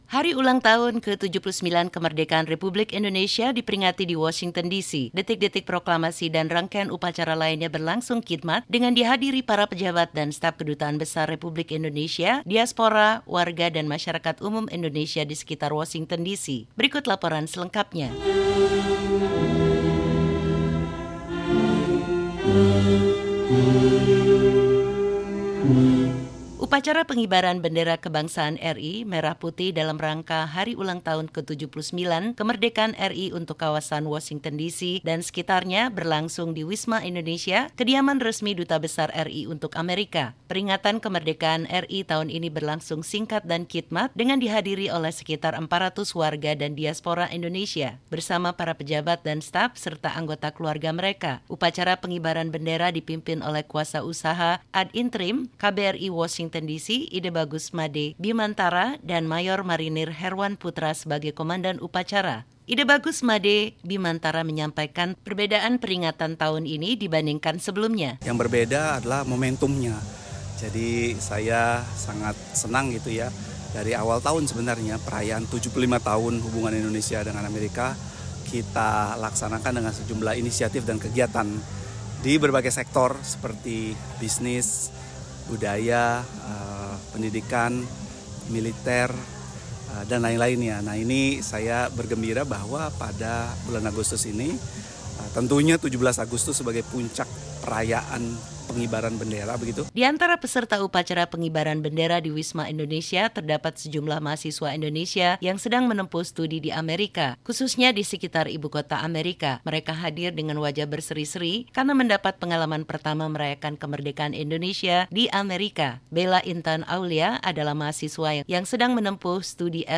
Upacara Hari Ulang Tahun (HUT) ke-79 Kemerdekaan Republik Indonesia berlangsung khidmat di Washington DC.